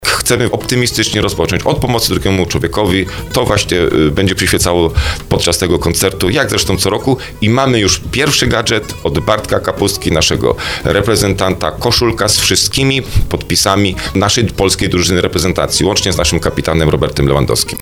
Jak mówi wójt Skrzyszowa Marcin Kiwior, koszulka z podpisami piłkarzy to nie tylko gratka dla kolekcjonerów i kibiców biało-czerwonych, ale przede wszystkim realna szansa na zwiększenie puli środków dla potrzebujących.